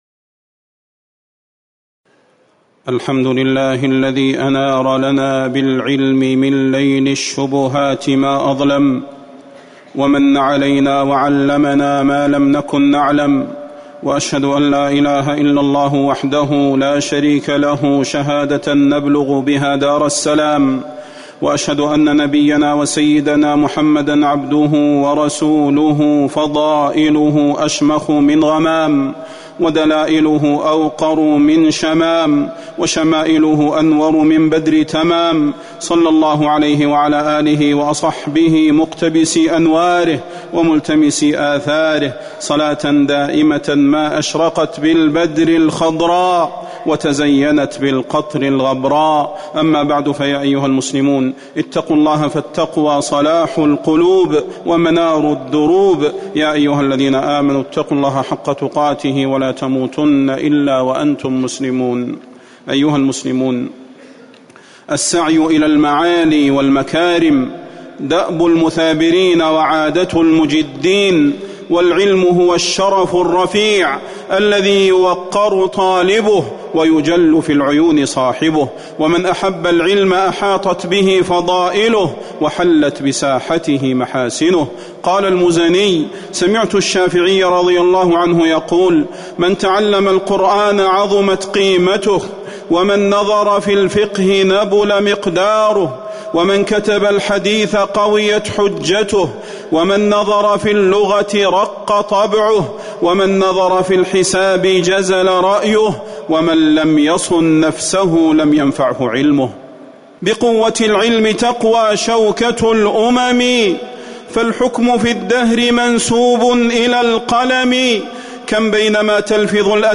فضيلة الشيخ د. صلاح بن محمد البدير
تاريخ النشر ٢٩ ذو الحجة ١٤٤٠ هـ المكان: المسجد النبوي الشيخ: فضيلة الشيخ د. صلاح بن محمد البدير فضيلة الشيخ د. صلاح بن محمد البدير وصايا للطلاب